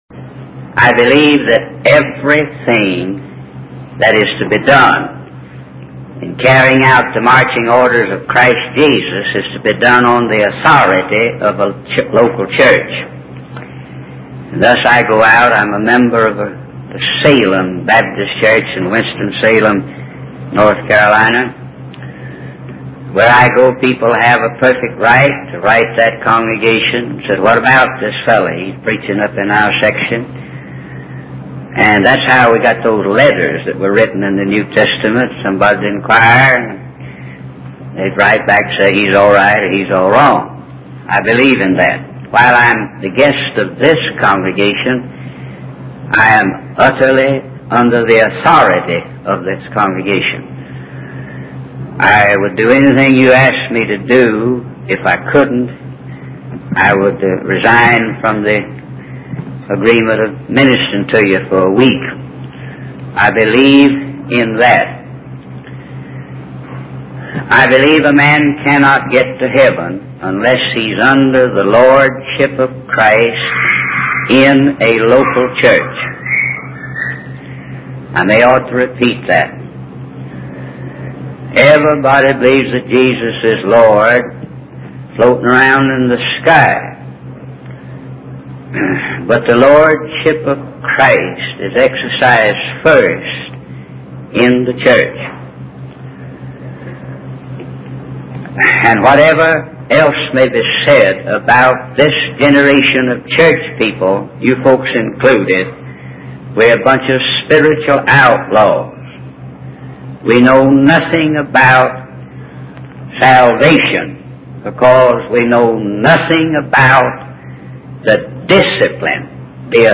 In this sermon, the preacher, who has been an evangelist for 36 years, emphasizes the importance of preaching the gospel and calling people to repentance.